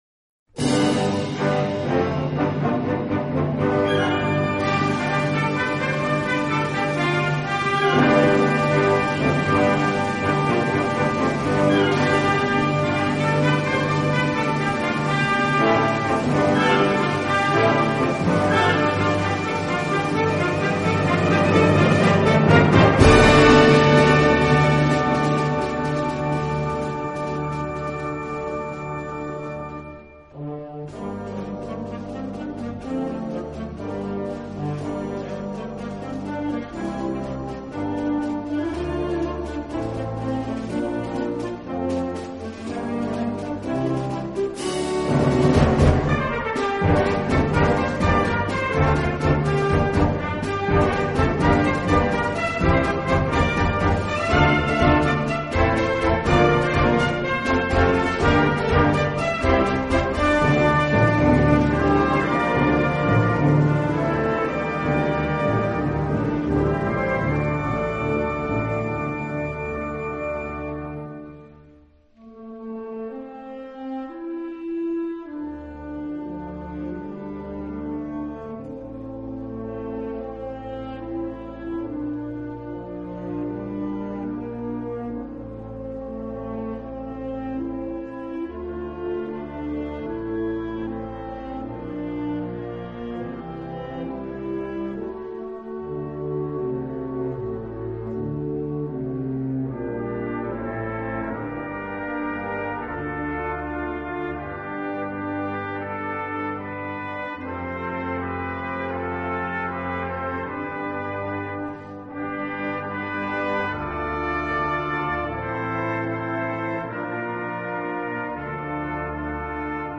23 x 30,5 cm Besetzung: Blasorchester Tonprobe